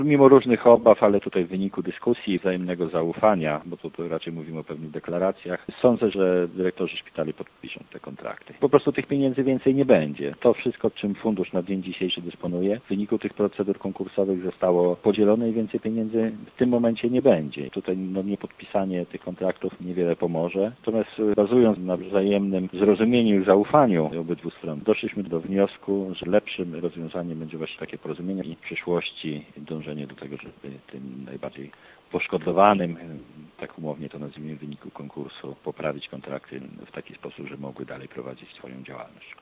Paweł Pikula nie ukrywa, że wszystkich wątpliwości, dotyczących finansowania szpitali nie udało się wyjaśnić, ale kontrakty powinny być podpisane: